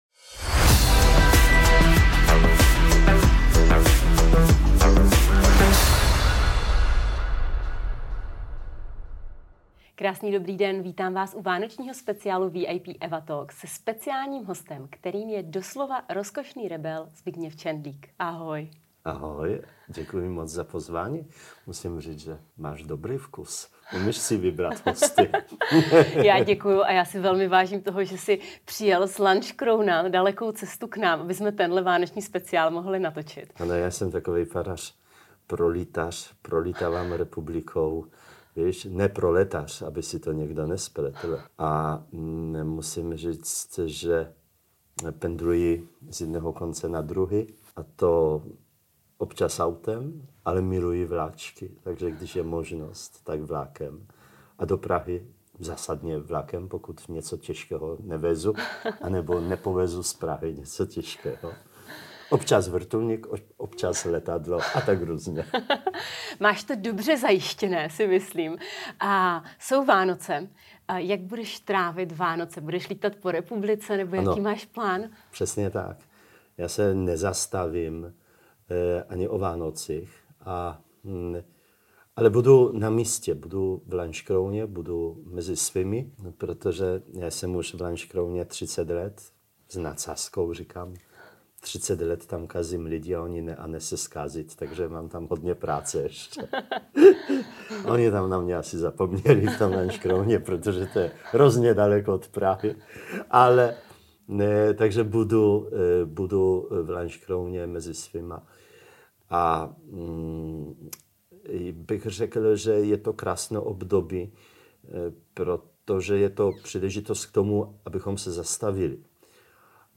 Před kamery tentokrát s vánočním poselstvím přišel katolický farář Zbigniew Czendlik. Ve svém poselství dává lidem naději, když říká: Tma nad světlem nikdy nezvítězí, a Vá...